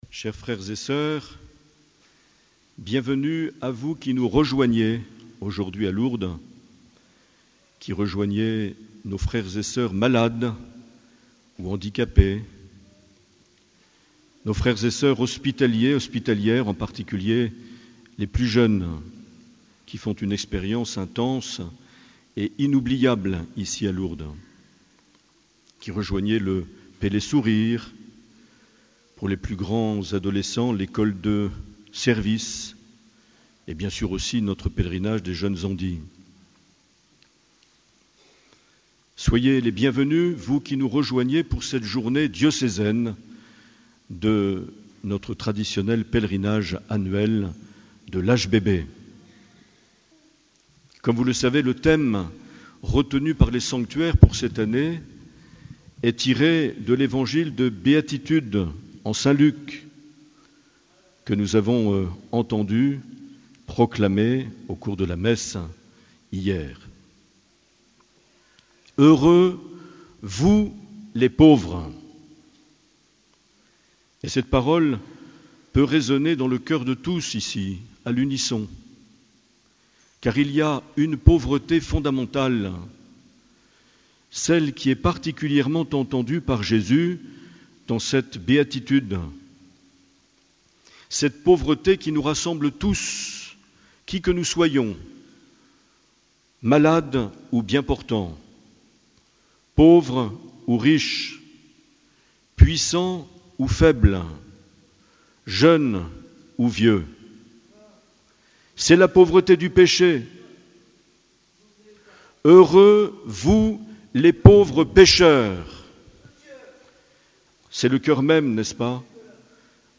15 septembre 2019 - Pèlerinage diocésain - Lourdes Sainte Bernadette
Une émission présentée par Monseigneur Marc Aillet